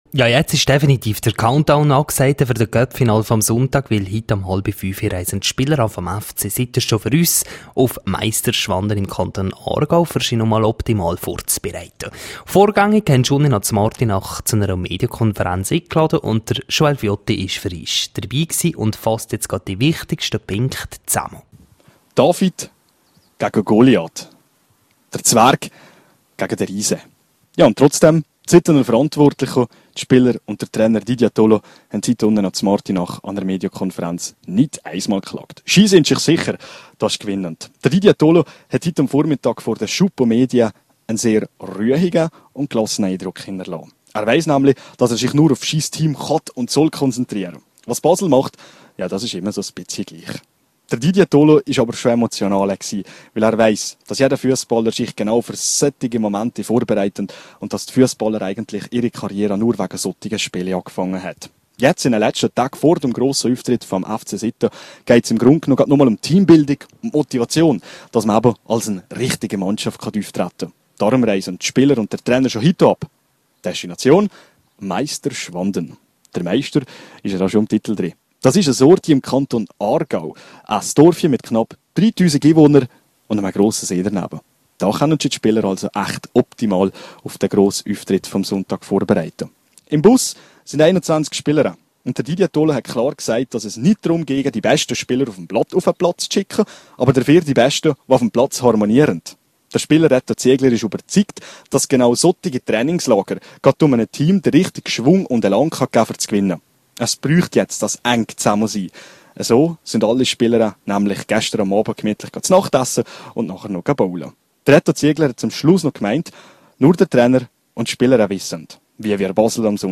rro zu Besuch an Fronleichnahm in Naters: Interview